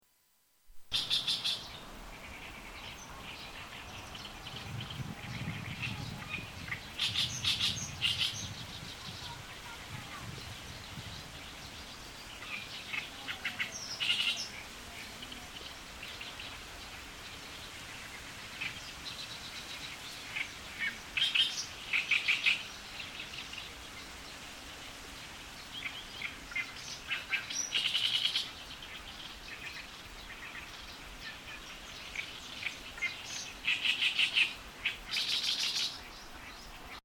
６月１日（土）にヨシキリが鳴くヨシ原の音風景をとりに出かけました。